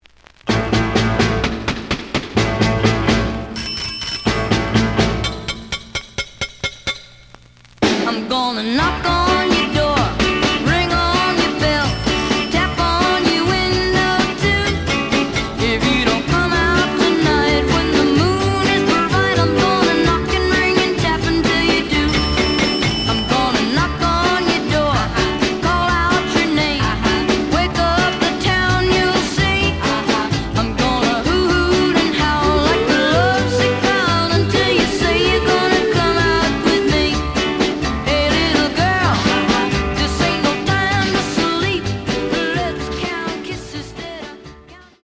試聴は実際のレコードから録音しています。
●Genre: Rhythm And Blues / Rock 'n' Roll